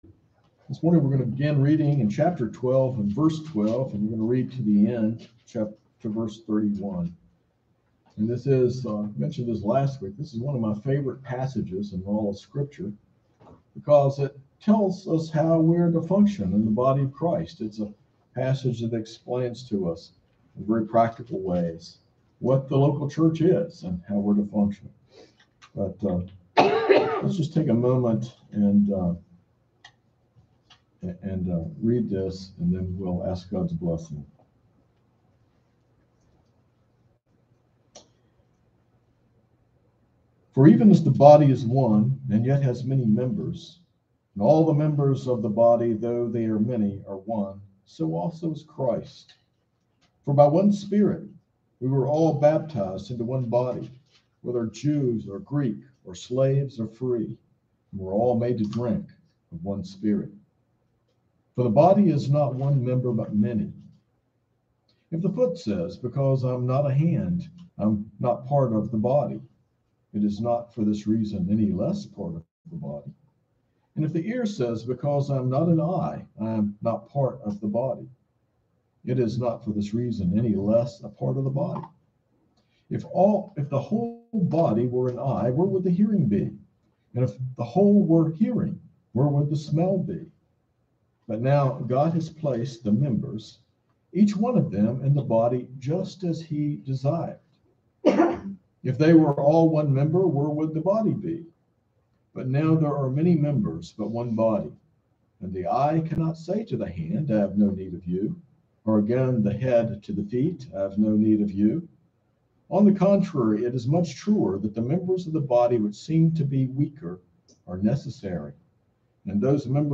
This sermon explores 1 Corinthians 12, emphasizing the local church as the unified body of Christ where every member is uniquely gifted and essential. It addresses pride and envy, calling believers to mutual love, service, and valuing each part of the body.